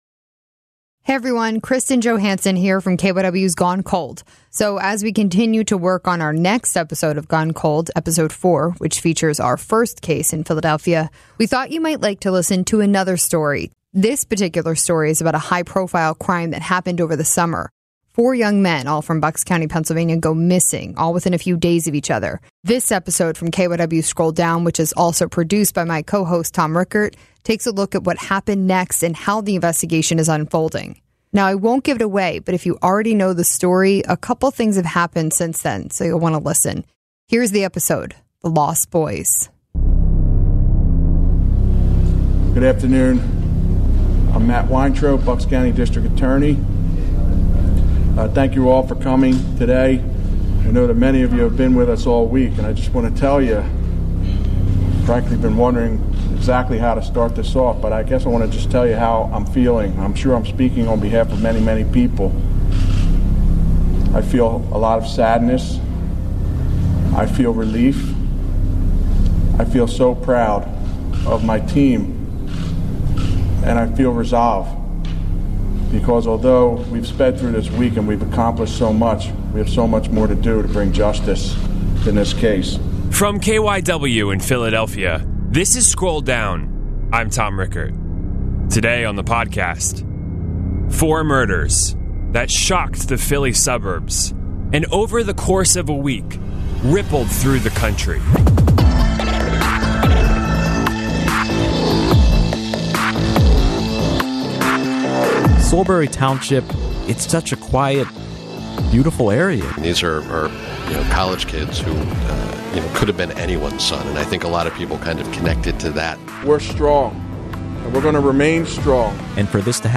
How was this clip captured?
in the KYW Newsradio studios in Philadelphia.